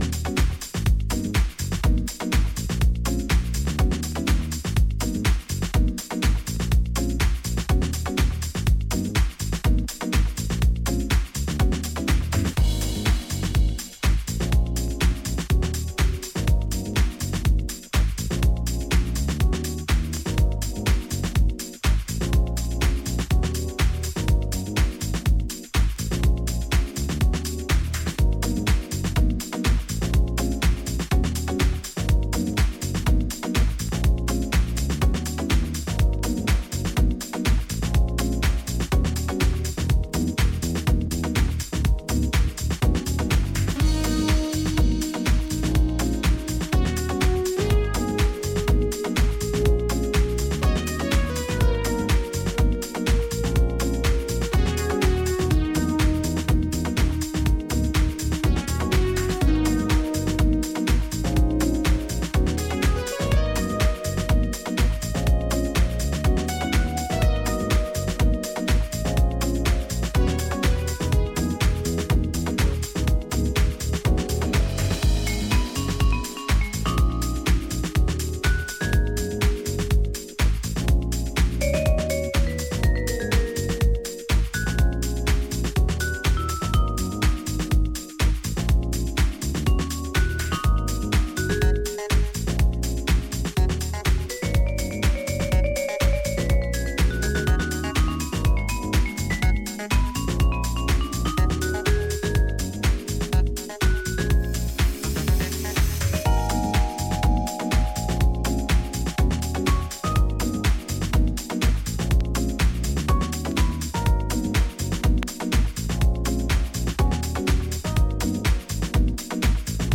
Italian deep house
lays down warm keys, rolling percussion and fluid basslines